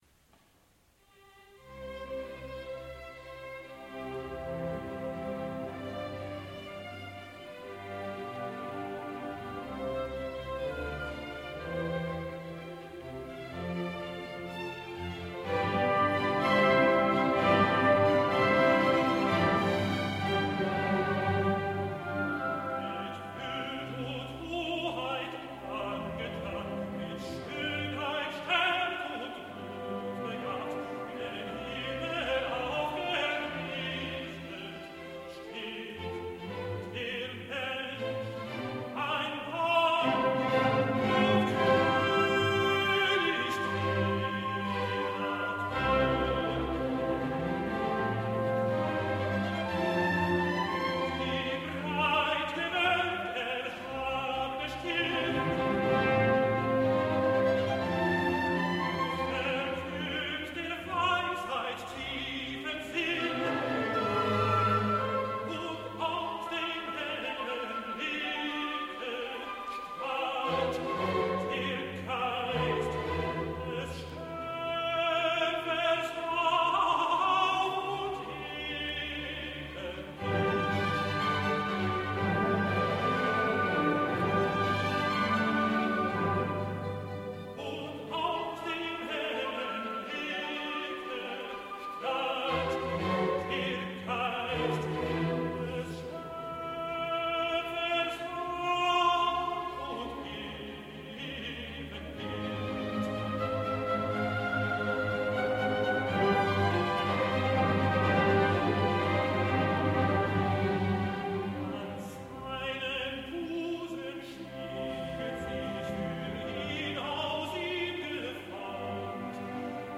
Tenor
• Mitglieder der Radiophilharmonie Saarbrücken und Kaiserslautern